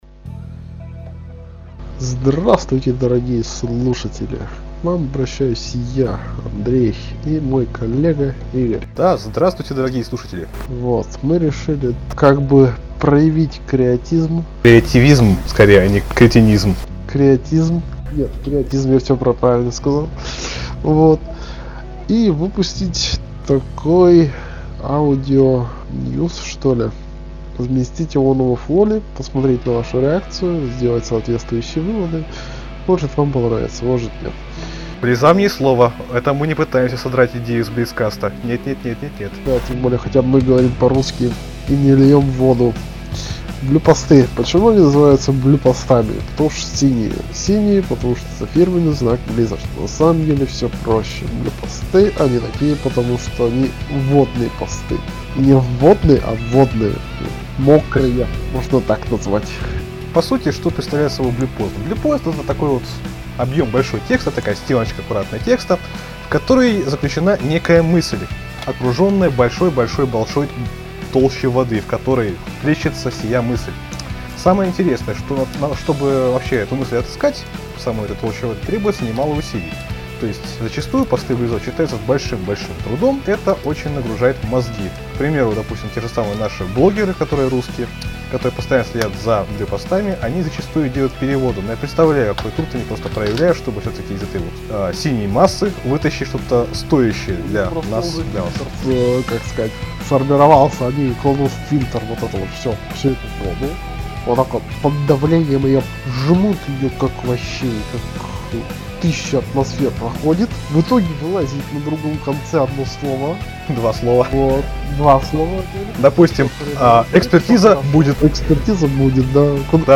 Собственно, пилотный выпуск одного из наших оригинальных трудов - последние аудионовости о WoW с размышлениями и шуточками. Запись первая, пилотная, с шумами и прочим хламом.